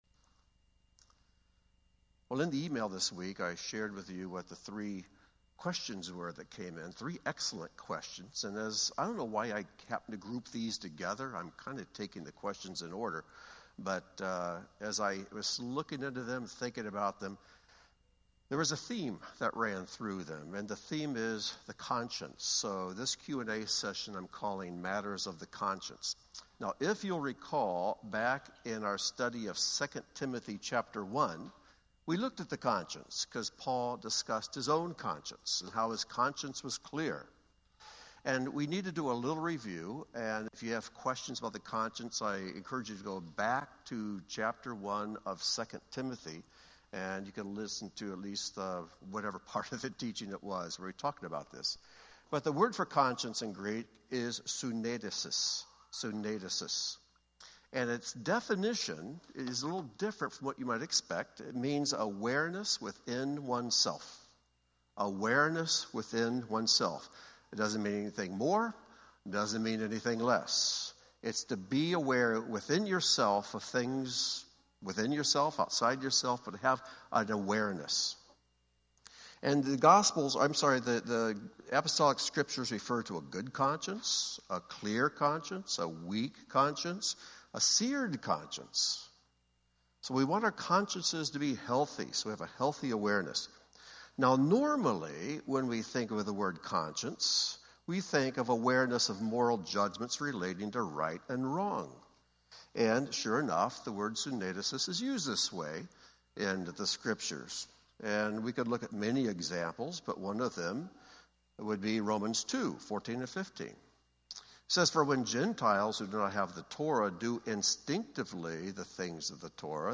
Join Beth Tikkun for a Q&A session tackling several matters of the conscience: meat sacrificed to idols, forgiving, and the Holy Spirit.